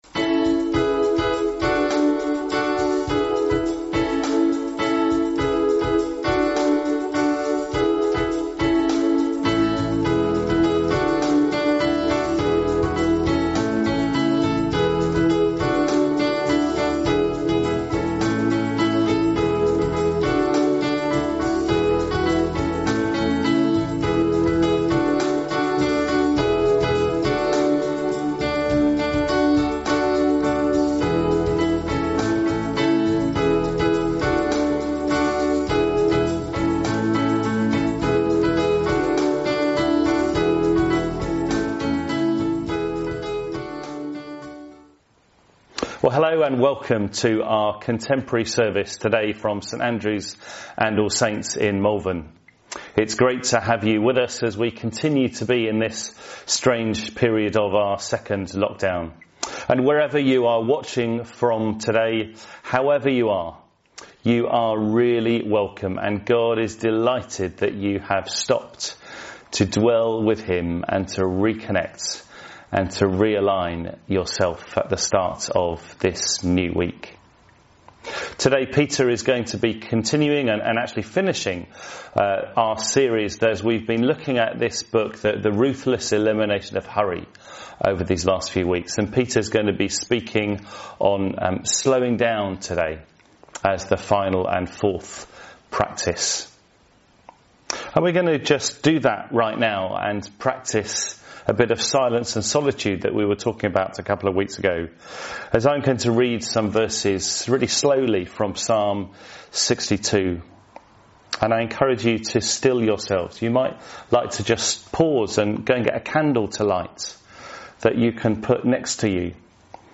A complete contemporary service.